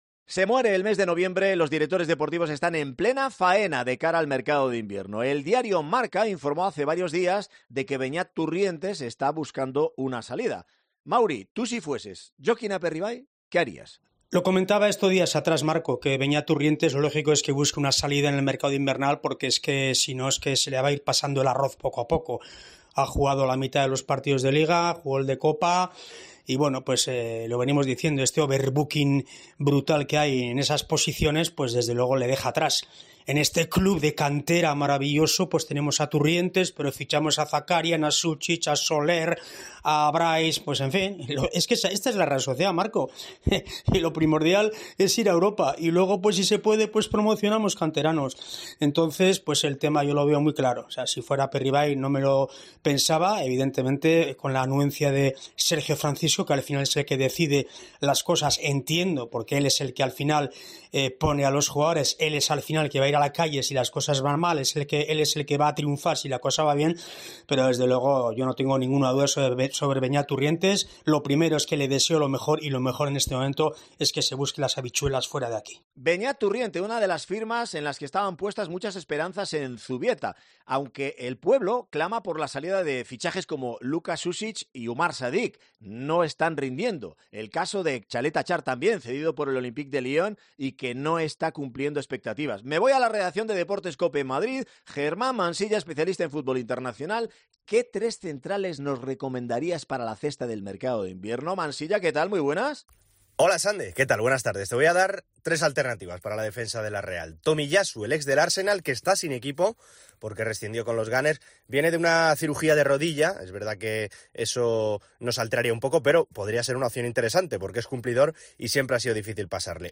Este análisis del mercado de fichajes de la Real Sociedad tuvo lugar en el programa Deportes COPE Gipuzkoa, que se puede seguir en directo de lunes a viernes de 15:25 h a 16:00 h en el 99.8 de la FM.